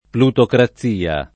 [ plutokra ZZ& a ]